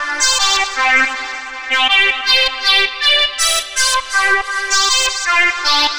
синтезатор звуки скачать, слушать онлайн ✔в хорошем качестве
💥💥💥 Аудио записи синтезатор - слушать звуки онлайн и скачать бесплатно ✔в хорошем качестве готовый аудио файл (sample, loop) синтезатор для создания музыки.